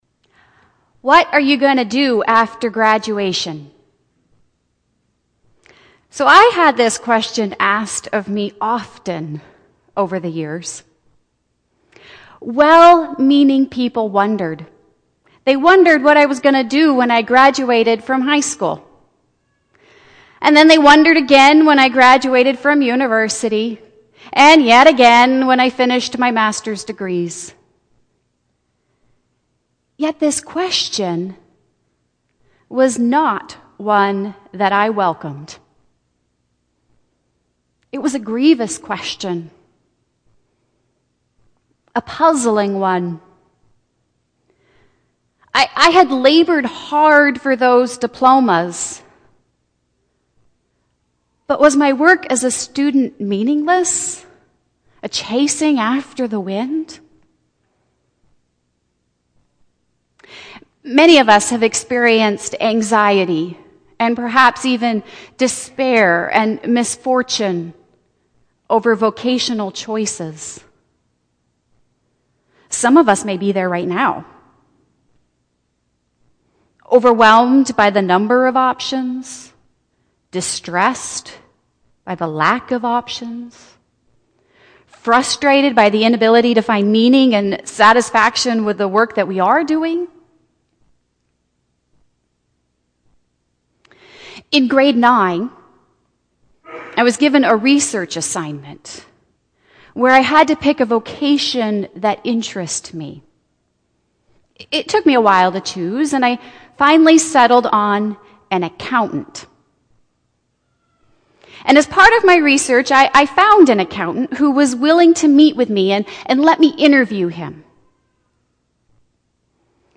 Services from St. Andrew's | St. Andrew’s Presbyterian Church